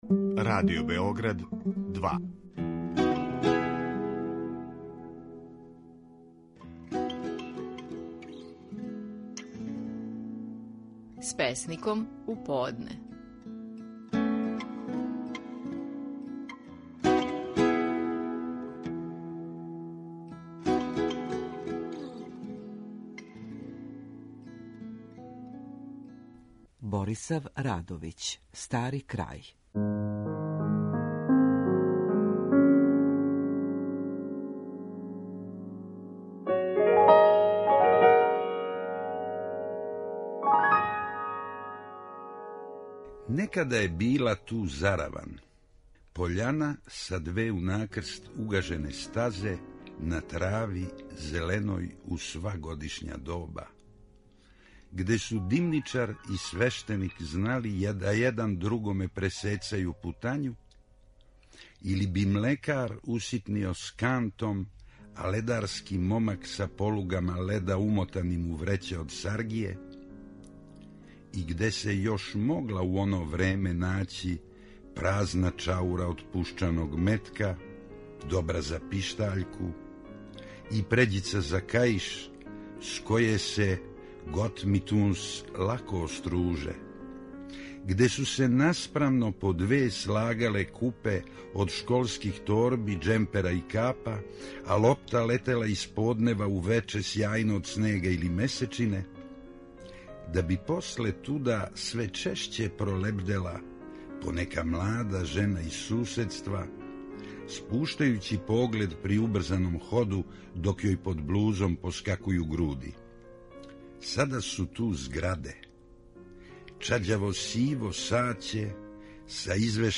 Стихови наших најпознатијих песника, у интерпретацији аутора.
Песник Борислав Радовић, казује стихове песме „Стари крај".